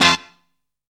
TOP BRASS.wav